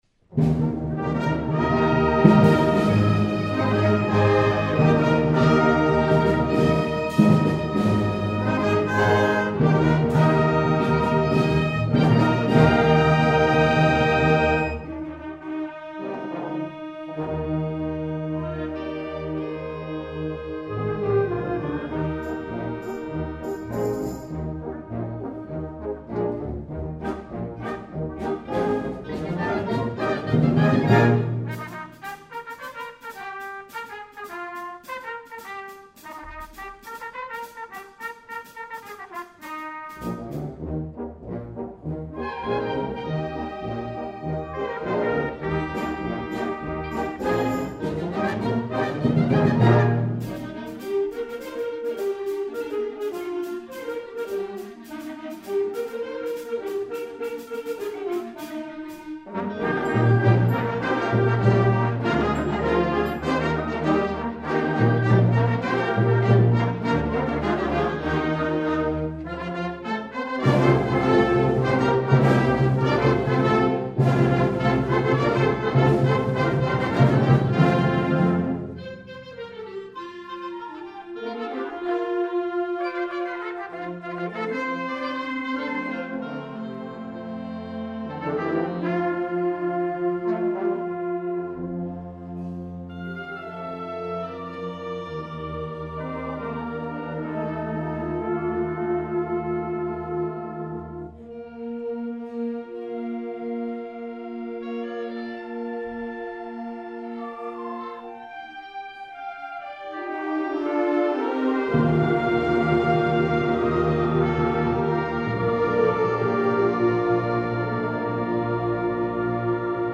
2009 Winter Concert
FLUTE
CLARINET
TRUMPET
PERCUSSION